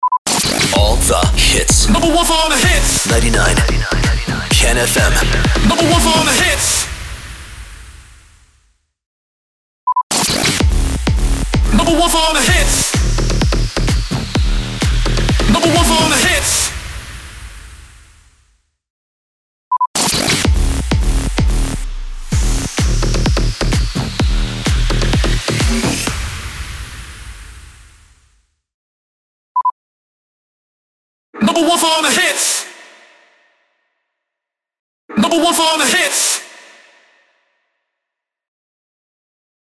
644 – SWEEPER – NUMBER ONE FOR ALL THE HITS
644-SWEEPER-NUMBER-ONE-FOR-ALL-THE-HITS.mp3